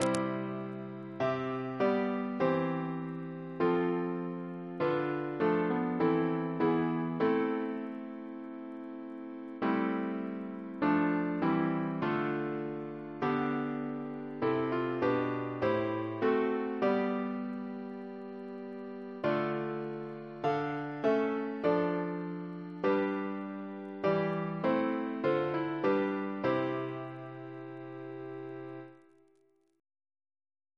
CCP: Chant sampler
Triple chant in C Composer: Sir Thomas H. W. Armstrong (1898-1994), Organist of Christ Church, Oxford Reference psalters: RSCM: 217